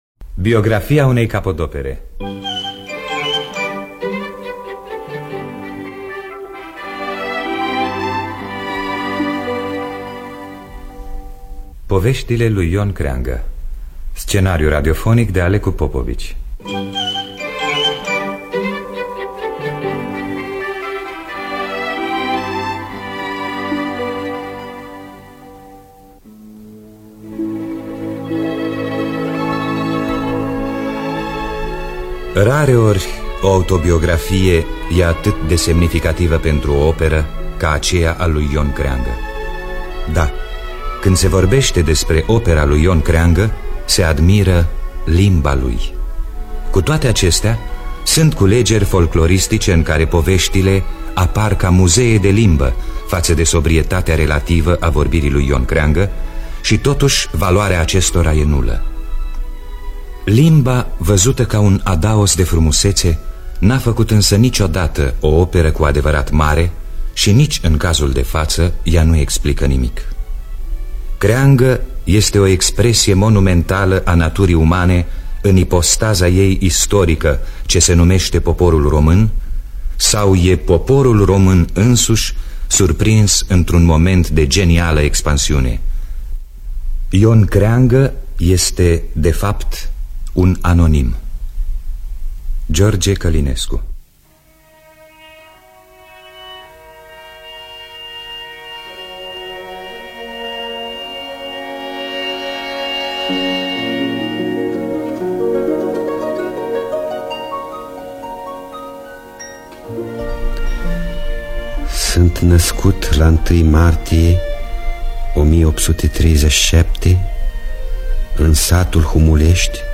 Scenariu radiofonic de Alecu Popovici.